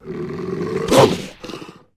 maschiff_ambient.ogg